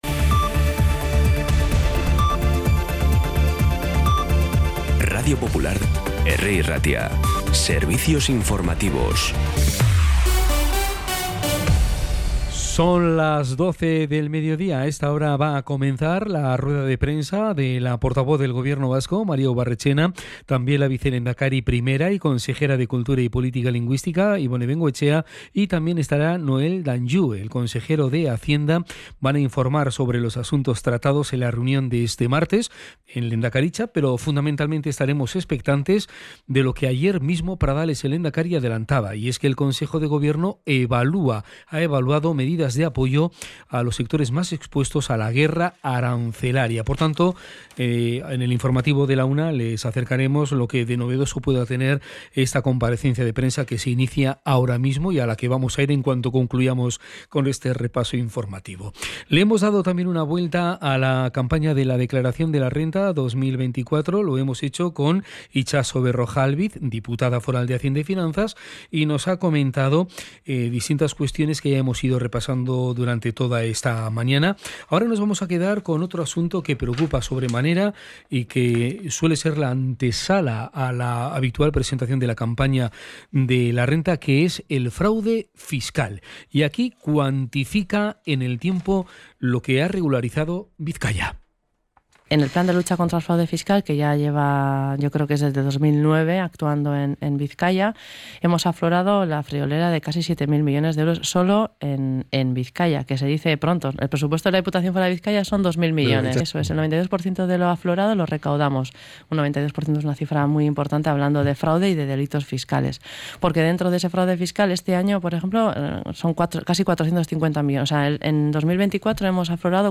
Las noticias de Bilbao y Bizkaia del 8 de abril a las 12
Los titulares actualizados con las voces del día. Bilbao, Bizkaia, comarcas, política, sociedad, cultura, sucesos, información de servicio público.